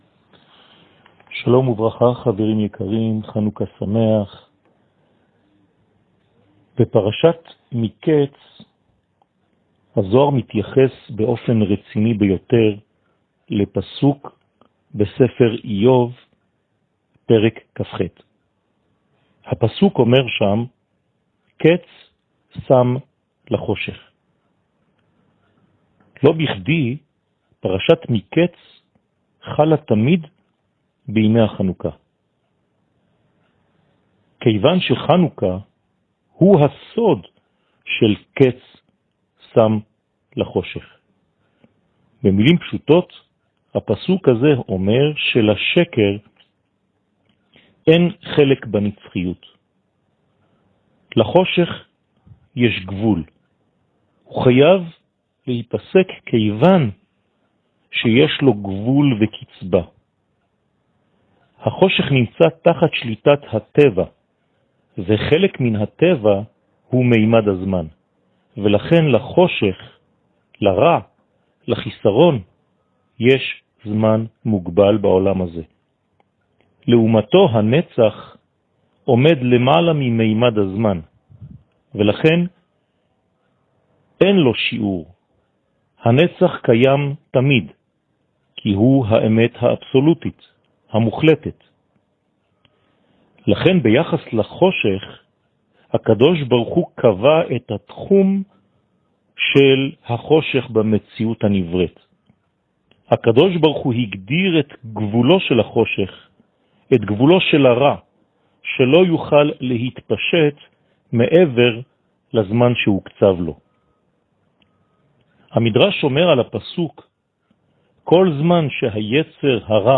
שיעורים